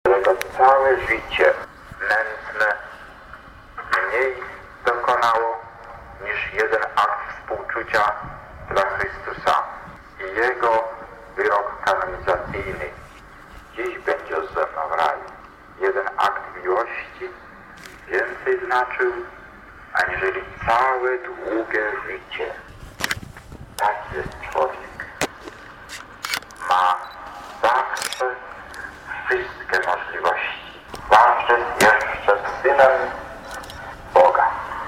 W czasie drogi krzyżowej można było usłyszeć archiwalne nagrania z oryginalnym głosem samego kard. Wyszyńskiego.
Prezentujemy fragmenty wypowiedzi kard. Stefana Wyszyńskiego: